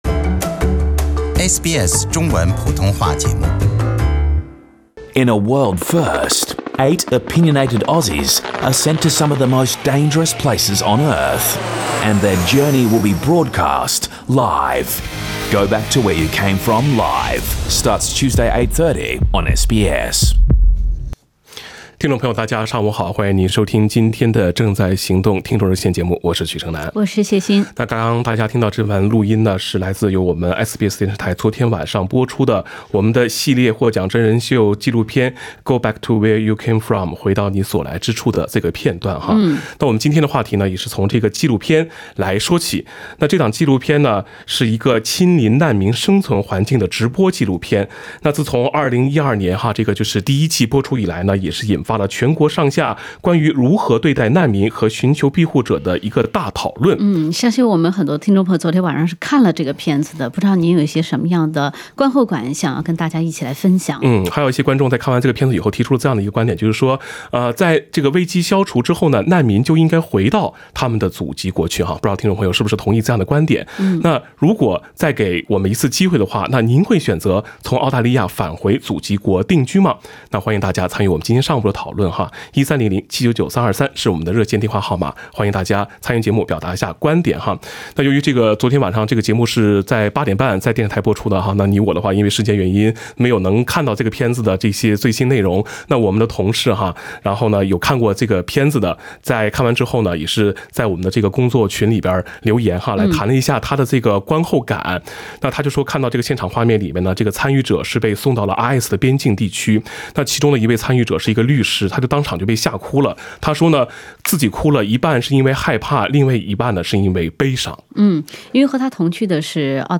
時政熱線節目《正在行動》逢週三上午8點30分至9點播出。